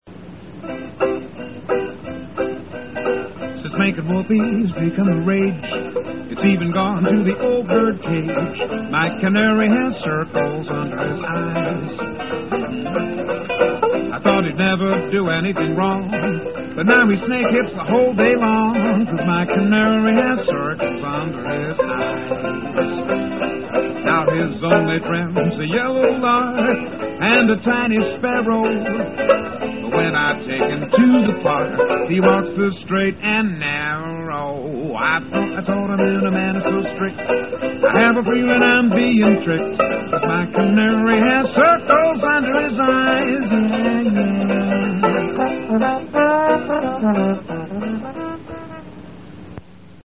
Launch washboard music below.